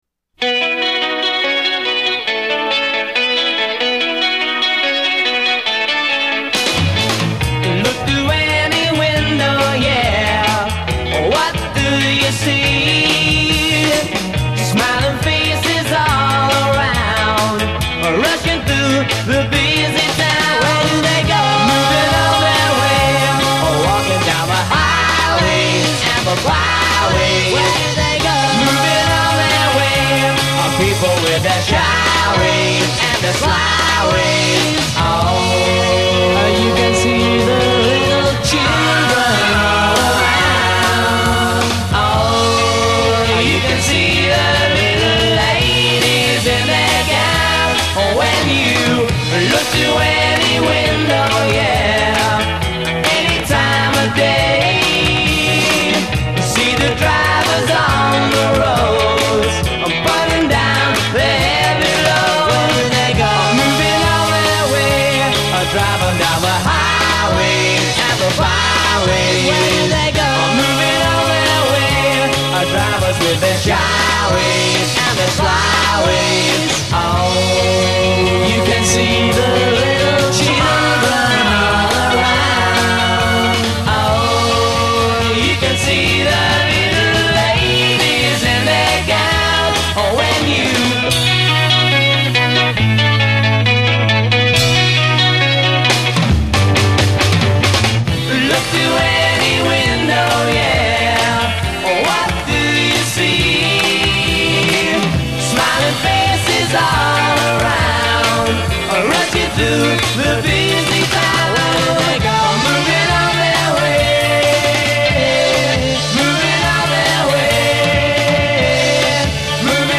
A verse 0: 16 joint harmony a